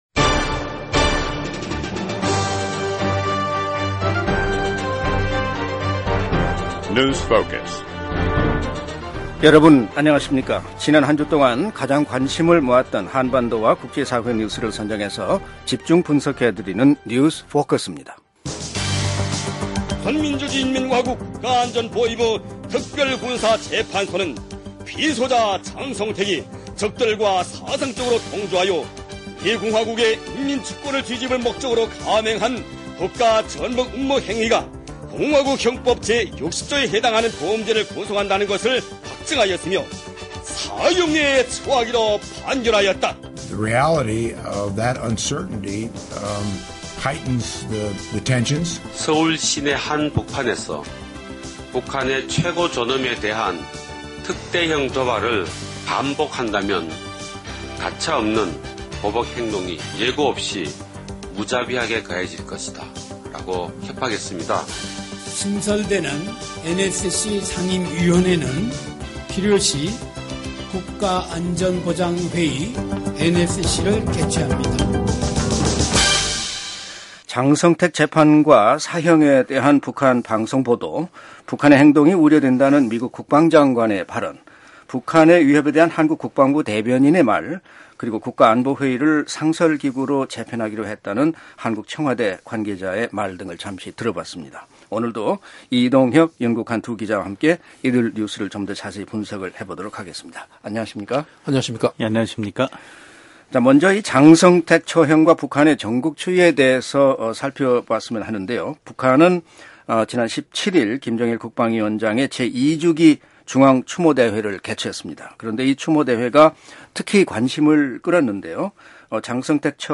지난 한 주 가장 관심을 모았던 한반도와 국제사회의 뉴스를 집중 분석해보는 뉴스 포커스입니다. 오늘은 북한 장성택 처형과 관련국들의 반응, 일본이 새로 채택한 국가안보지침과 방위력 증강 계획에 대해 알아봅니다.